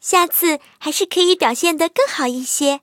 M22蝉MVP语音.OGG